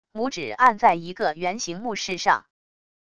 拇指按在一个圆形木饰上wav音频